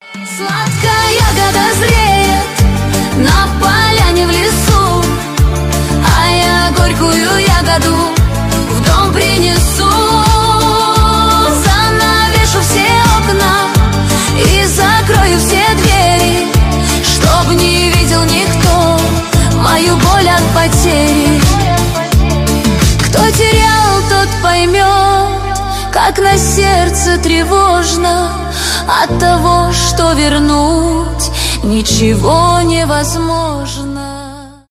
поп , шансон